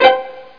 AKKORD_B.mp3